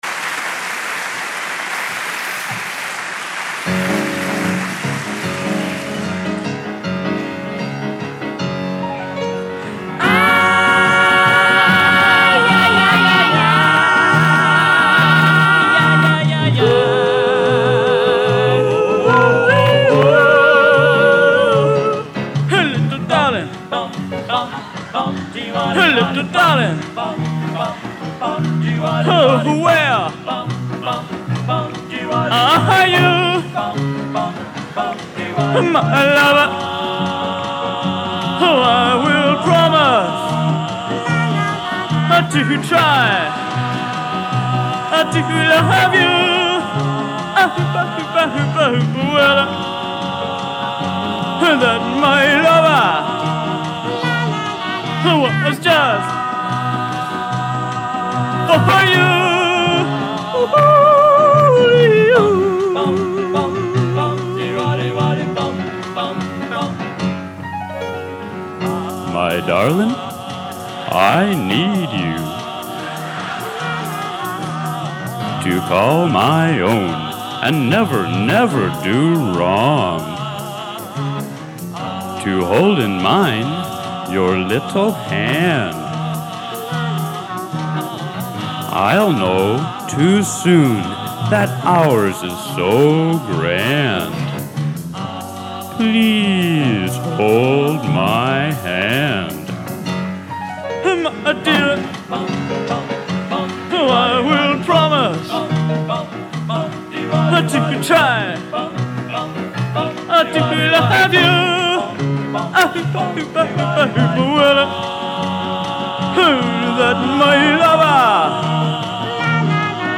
Genre: Doo Wop | Type: End of Season